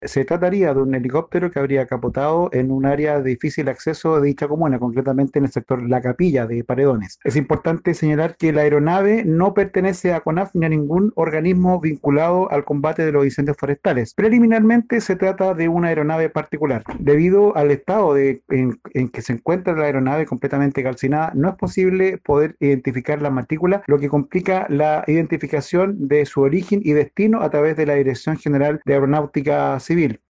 Esto informó el delegado presidencial Regional Fabio López en horas de la tarde del domingio tras conocerse de este lamentable hecho